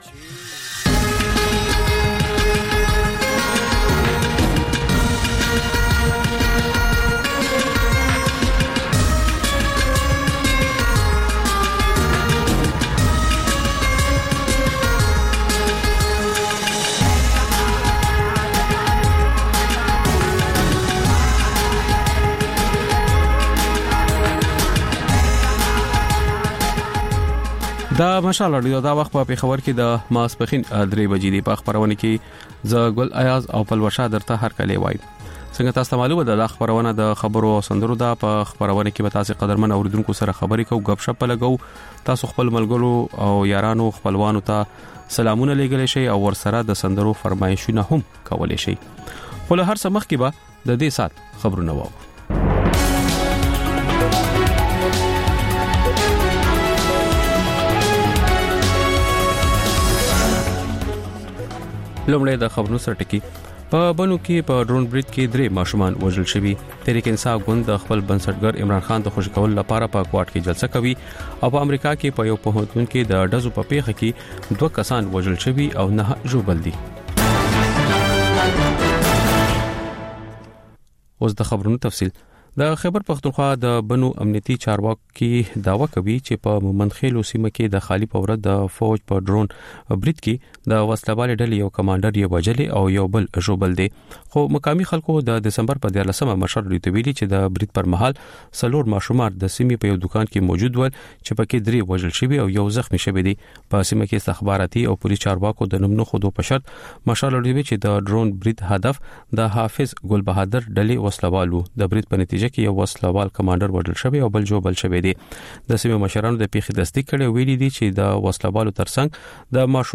ژوندۍ واورئ - مشال راډیو - مشال راډیو